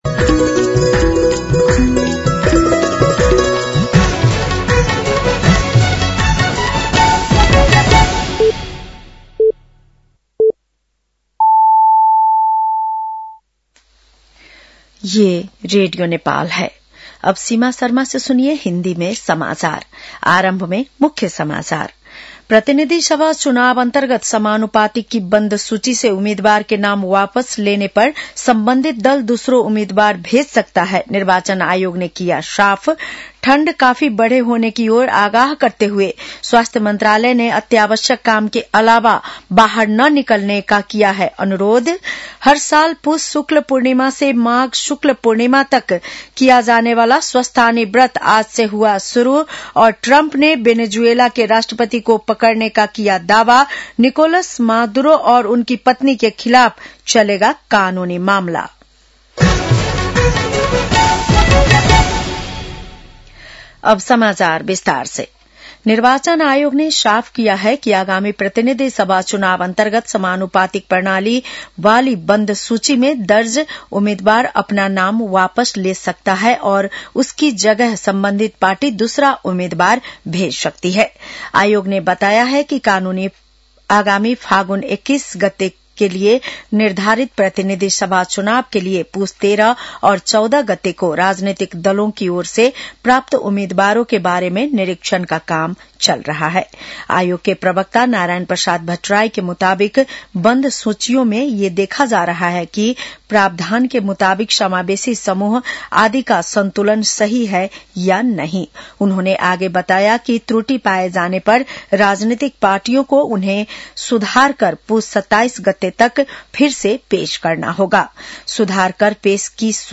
बेलुकी १० बजेको हिन्दी समाचार : १९ पुष , २०८२